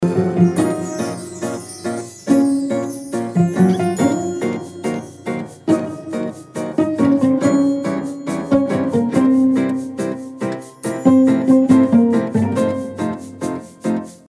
زنگ موبایلملایم بی کلام